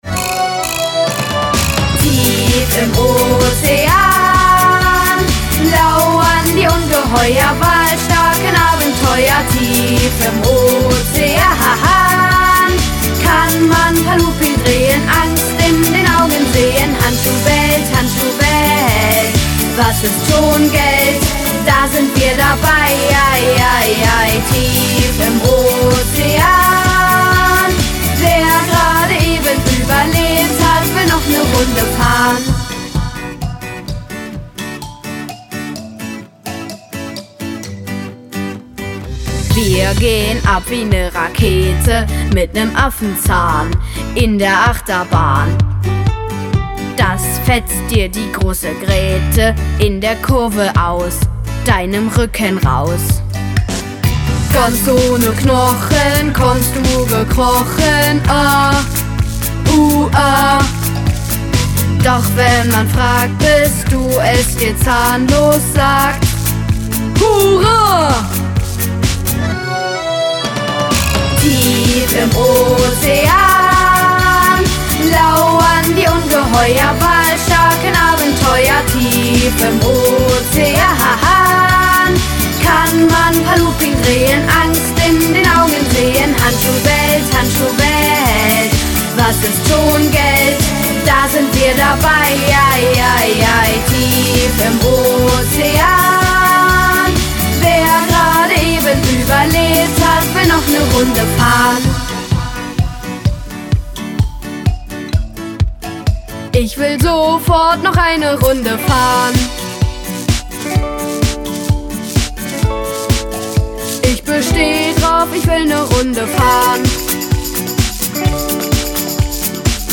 Doku VO 2022 Hörspiel 2022 nachdenklich 2022